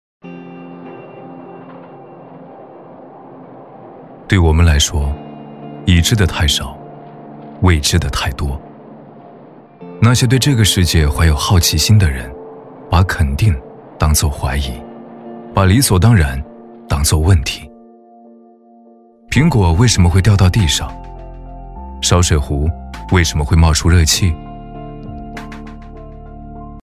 100%人工配，价格公道，配音业务欢迎联系：
超A男200号
【广告】励志 追问的力量
【广告】励志  追问的力量.mp3